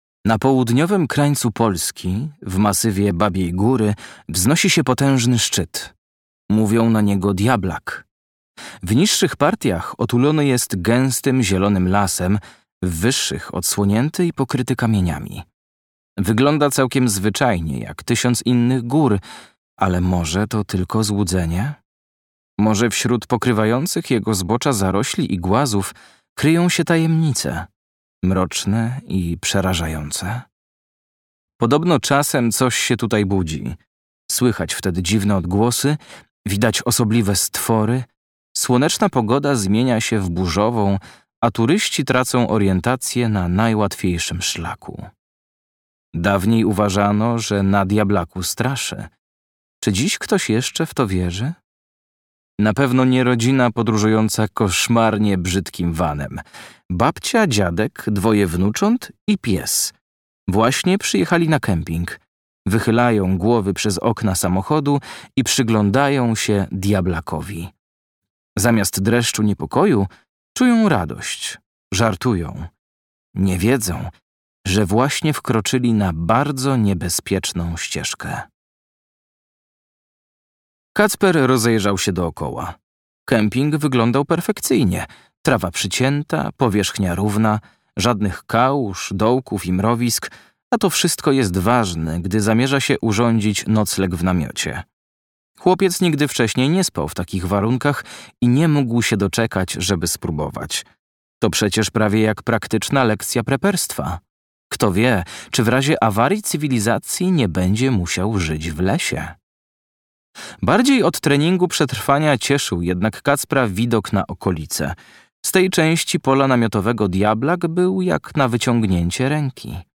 Wakacje z Koszmarkiem, tom 2 - Justyna Drzewicka - audiobook